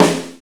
RX SNARE.wav